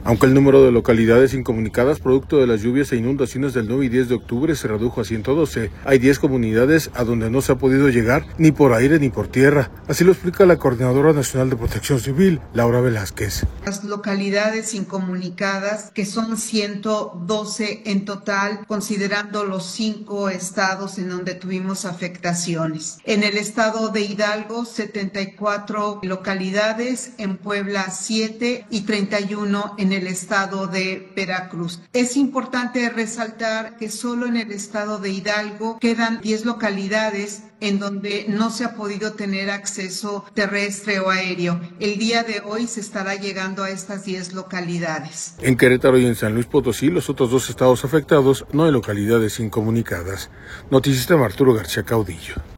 audio Aunque el número de localidades incomunicadas producto de las lluvias e inundaciones del 9 y 10 de octubre, se redujo a 112, hay diez comunidades a donde no se ha podido llegar ni por aire ni por tierra, así lo explica la coordinadora nacional de Protección Civil, Laura Velázquez.